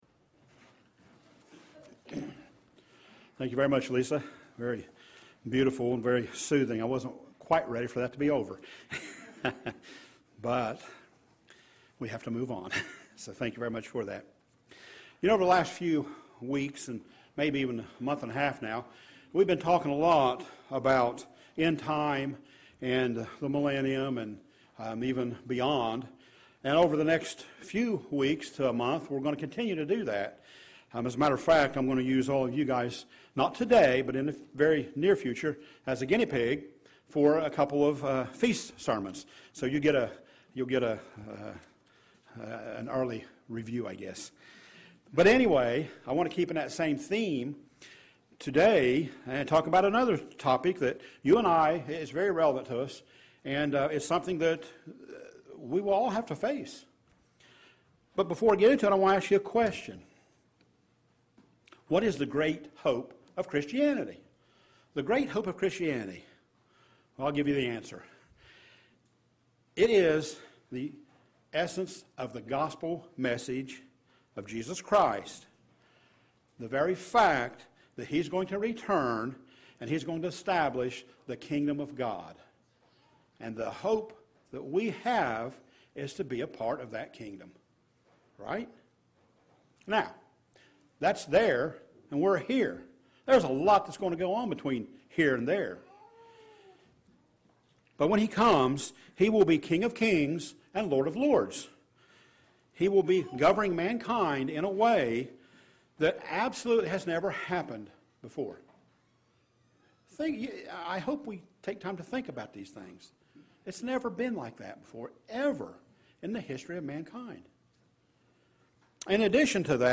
Given in Portsmouth, OH Paintsville, KY